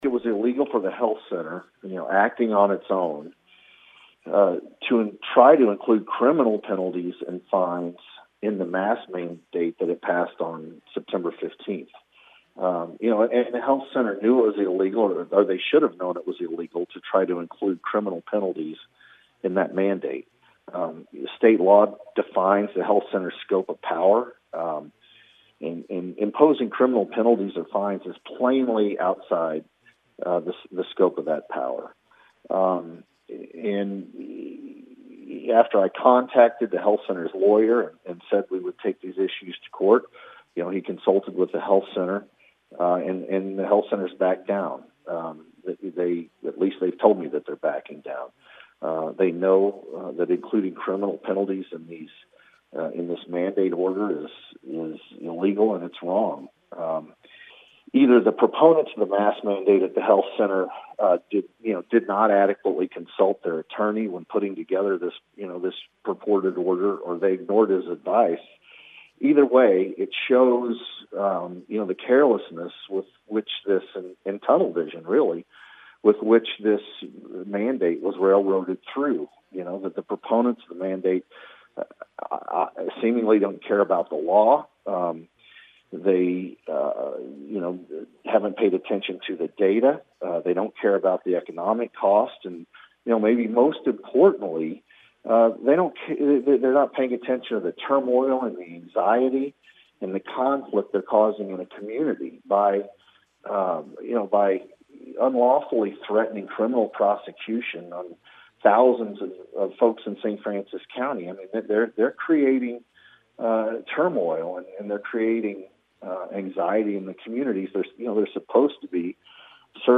statement to KFMO B104 News: